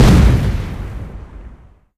爆炸.ogg